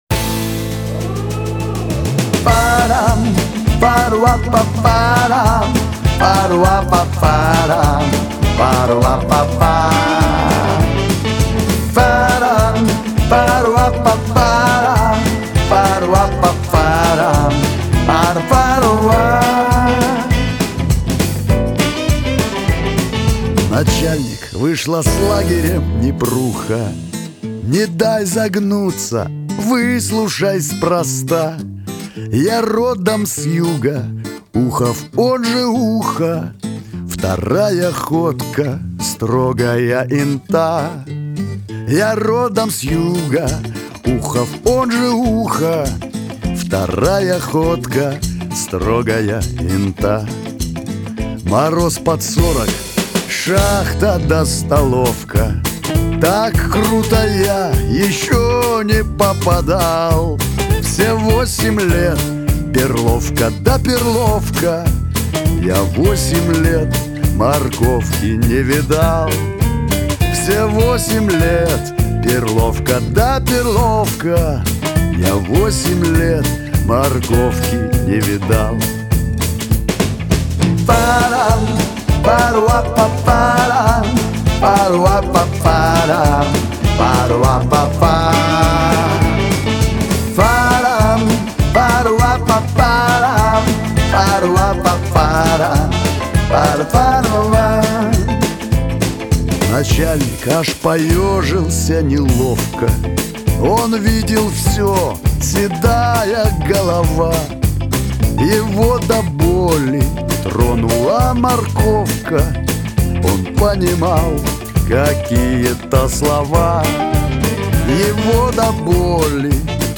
ансамбль
грусть
Шансон